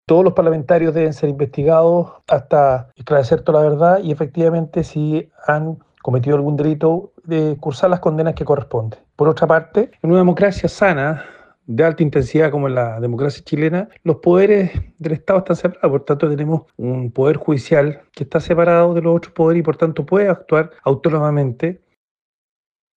Por su parte, el diputado y presidente de Amarillos por Chile, Andrés Jouannet, sostuvo: “Pese al fuero, nadie está sobre la ley”.